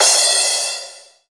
Index of /90_sSampleCDs/Sound & Vision - Gigapack I CD 1 (Roland)/CYM_CRASH mono/CYM_Crash mono
CYM CRA04.wav